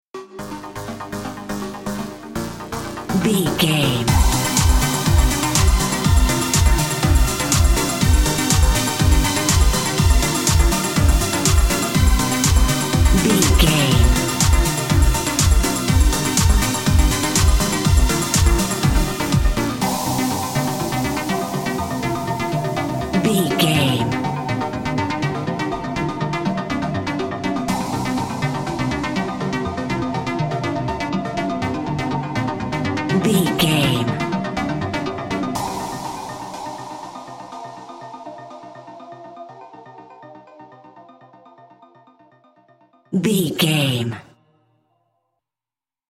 Aeolian/Minor
groovy
uplifting
energetic
cheerful/happy
synthesiser
drum machine
house
electro dance
techno
synth bass
upbeat